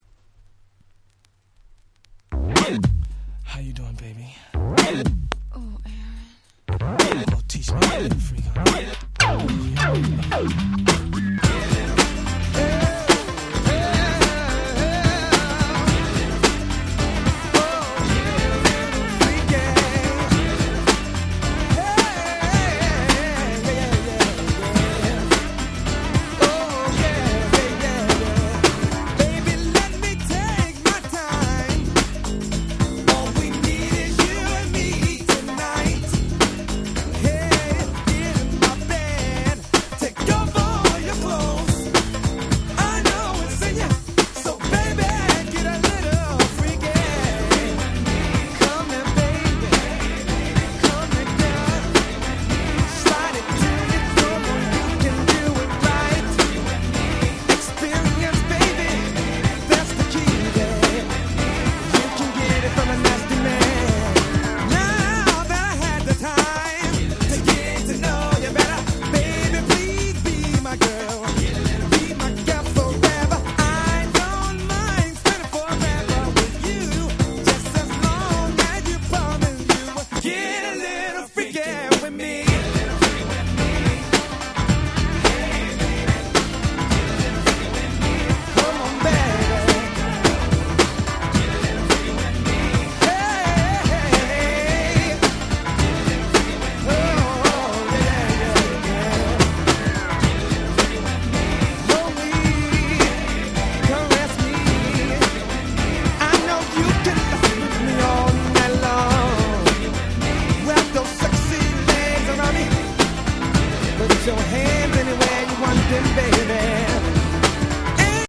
93' Nice R&B/New Jack Swing LP !!
A面はハネ系New Jack Swing中心、B面は激甘Slow中心。